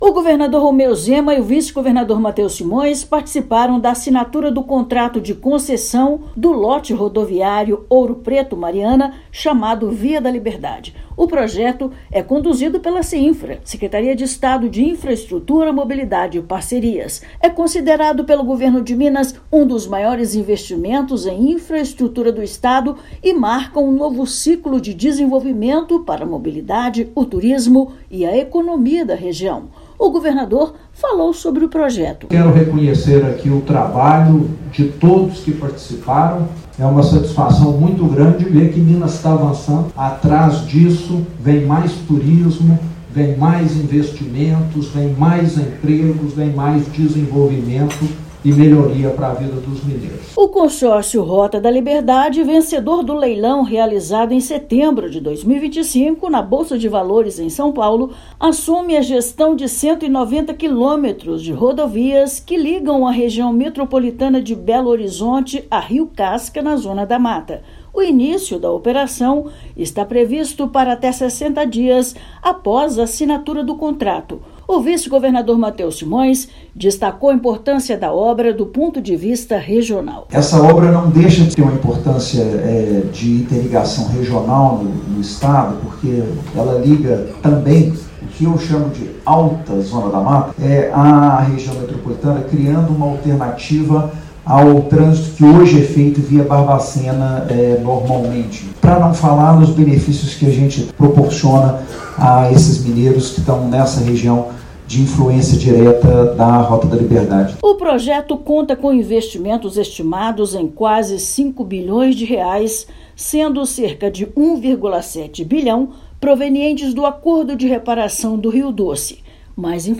Gestão do trecho terá início em até 60 dias; obras incluem duplicação de pistas e reforço na segurança viária. Ouça matéria de rádio.